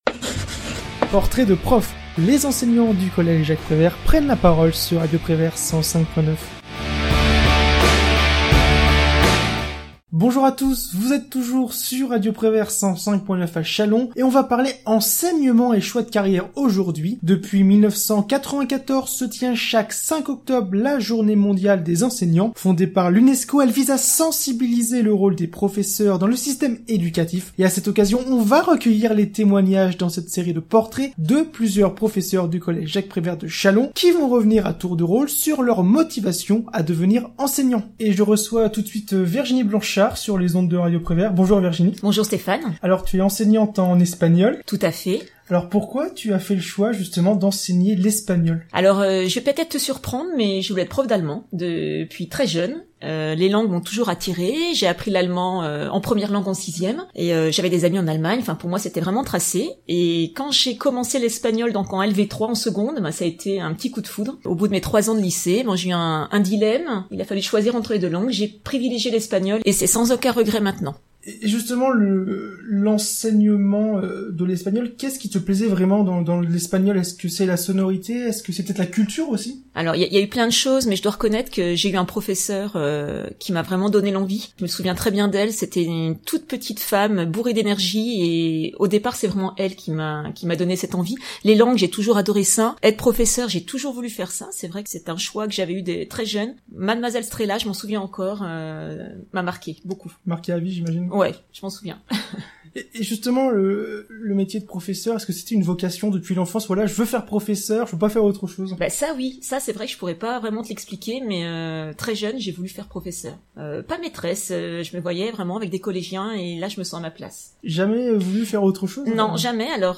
L'Interview / Émissions occasionnelles Podcasts
À cette occasion, nous avons recueilli les témoignages de plusieurs professeurs du Collège Jacques Prévert de Chalon. Ils reviennent à tour de rôle sur leurs motivations à devenir enseignant.